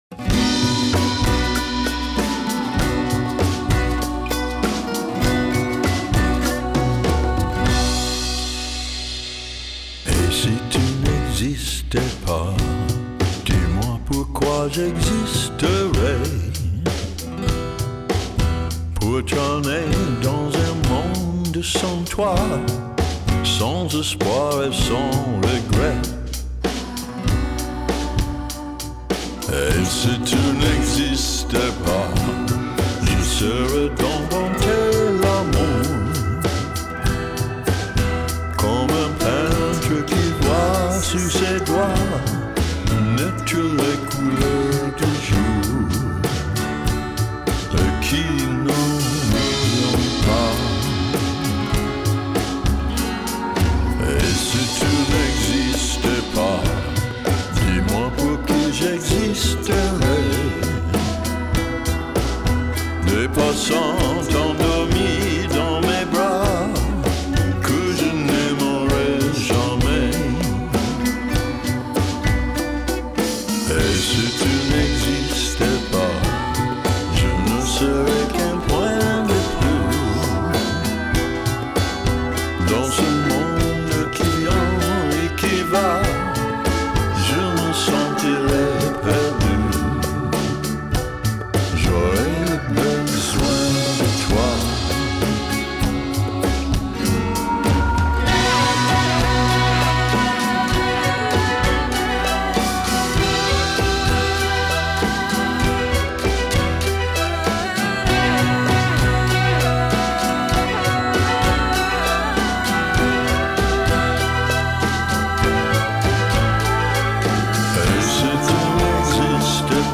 Genre: Vintage Lounge, Chanson, Vocal Jazz